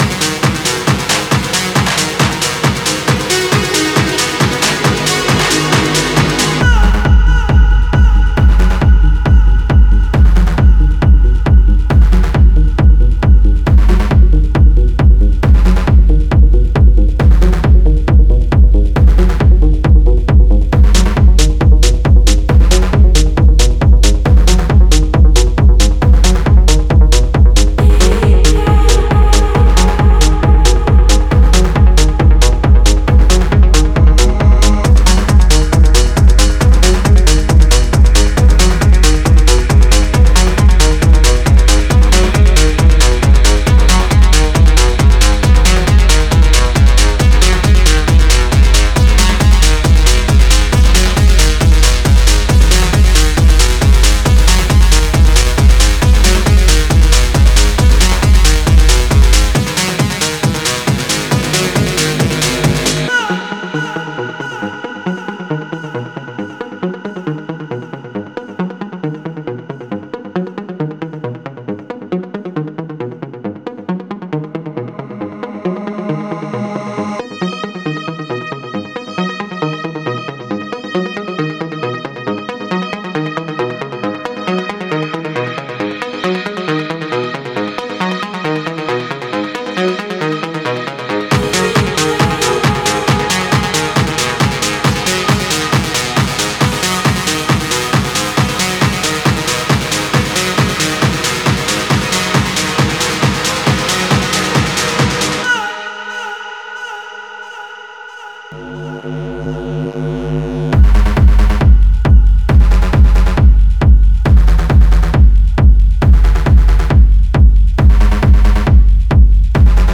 Styl: Techno